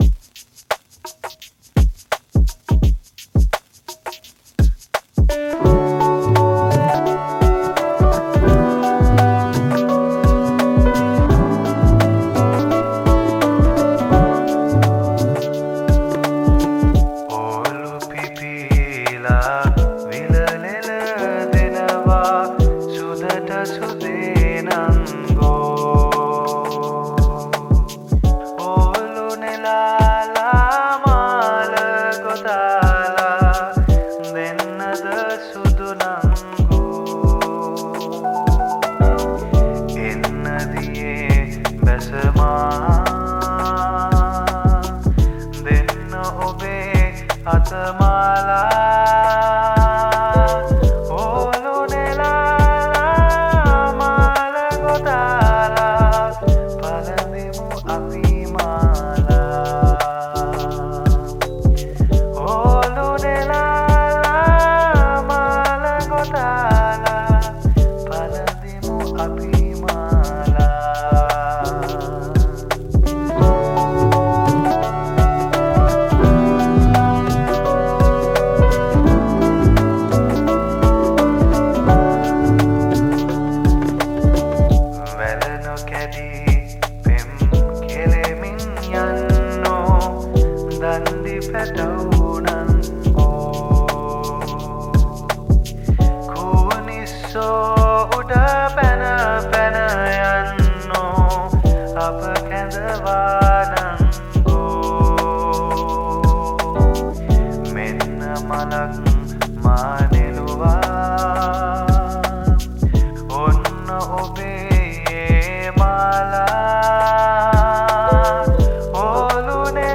Cover Vocals
Percussion
lofi chill chilled